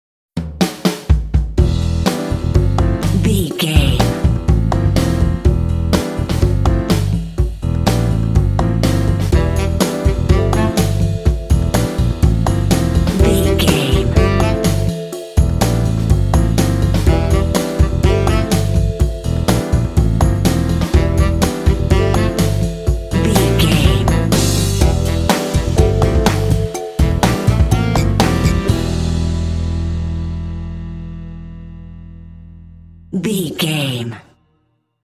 Phrygian
funky
happy
bouncy
groovy
piano
drums
saxophone
bass guitar
jazz
Funk